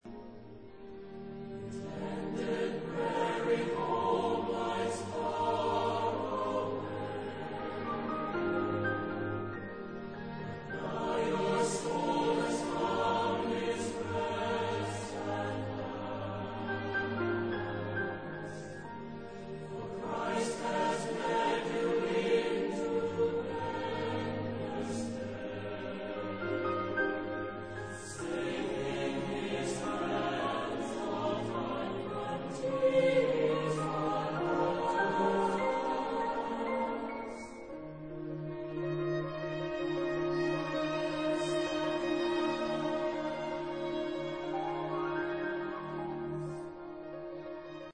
Genre-Style-Form: Secular
Type of Choir: SATB  (4 mixed voices )
Instrumentation: Keyboard + String Band